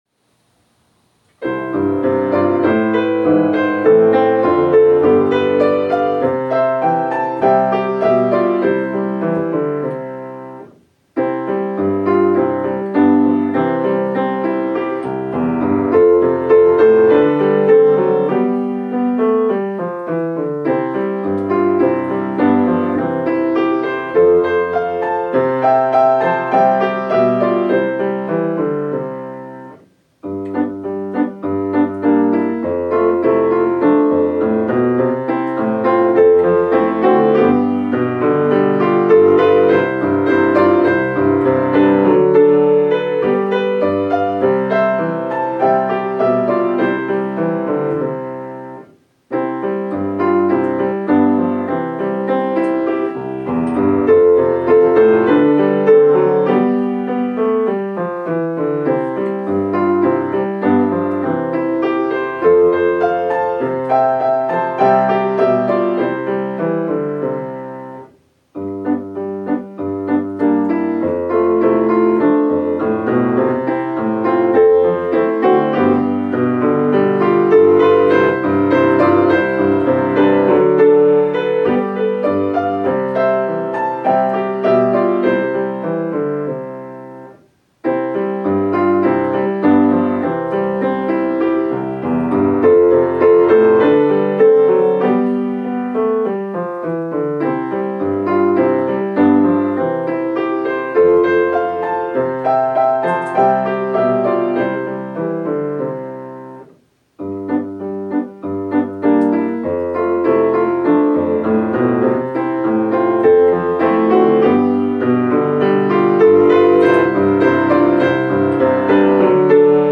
春日小学校の校歌です。